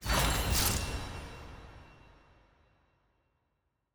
sfx-exalted-chase-1x-roll-reward-appear-anim.ogg